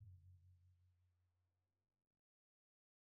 Timpani1_Hit_v1_rr2_Sum.wav